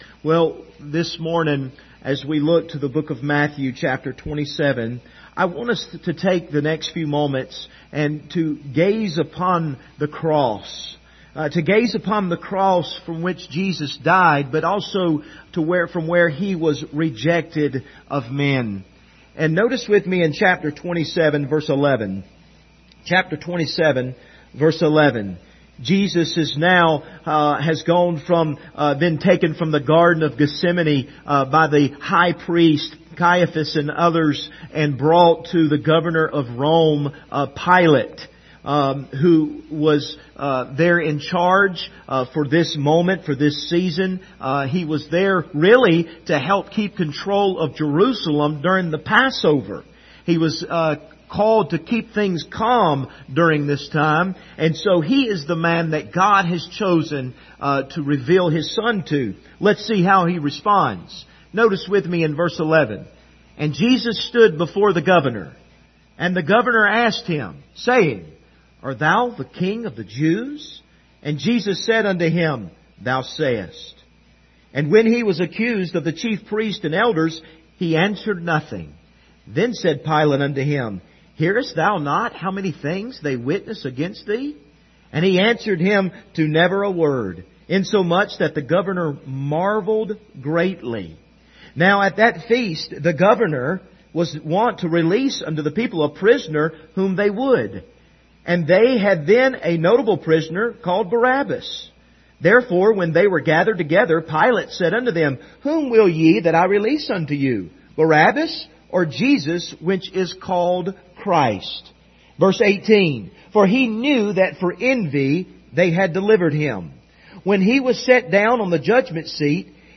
Passage: Matthew 27:11-24 Service Type: Sunday Morning Topics